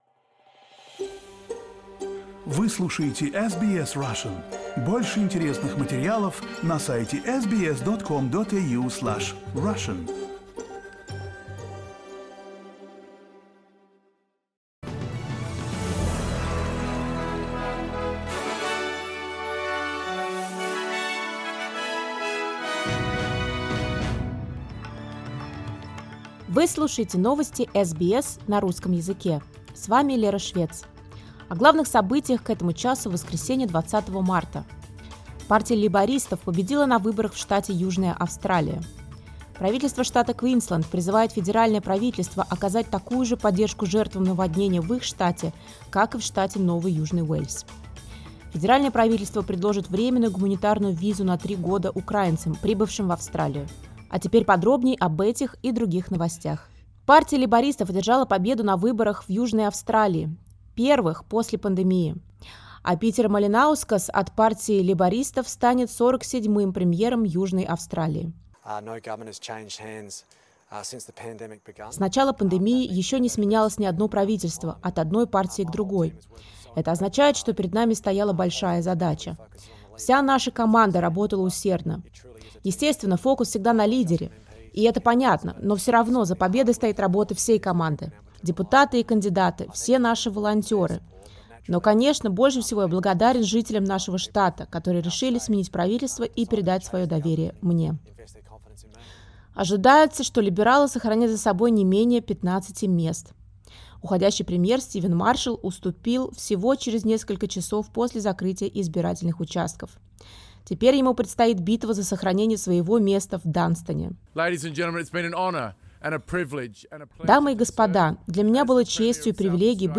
SBS news in Russian — 20.03